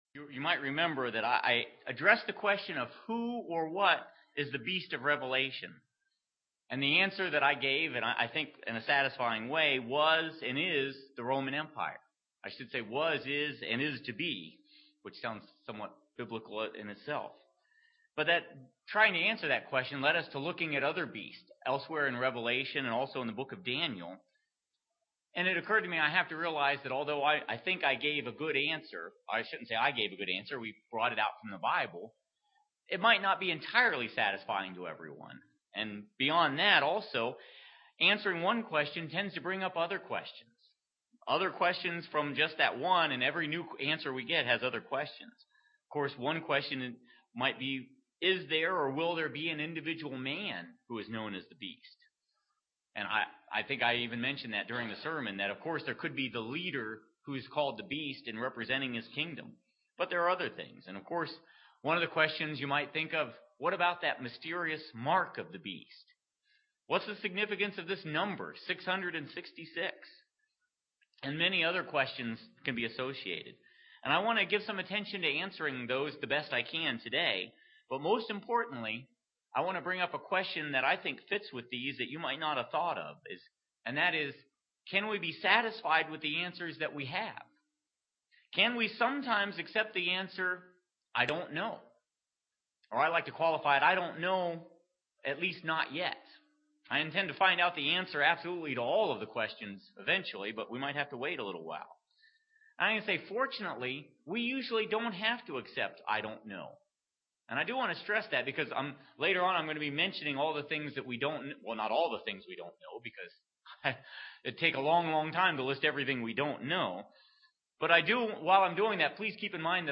We will continue the sermon from last week, covering the topic the beast and beast power. Can we be satisfied with accepting the answer "I don't know" to all questions?